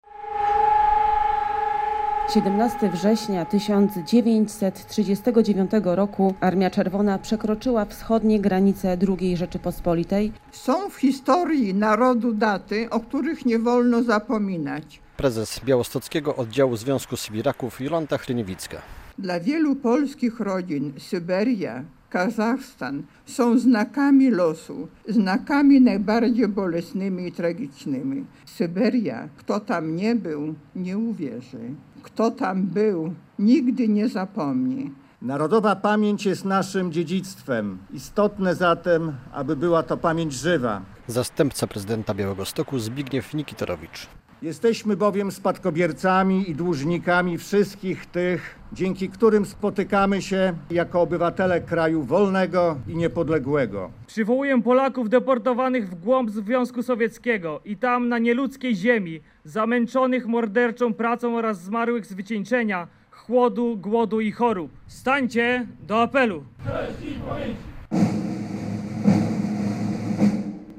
Z tej okazji w Białymstoku sybiracy, wojsko i przedstawiciele władz, przed Pomnikiem Matki Sybiraczki oddali hołd poległym i deportowanym na nieludzką ziemię.
Zastępca prezydenta Białegostoku Zbigniew Nikitorowicz przypomniał, że dziś ważne jest, by pamięć o tamtych wydarzeniach była pamięcią żywą.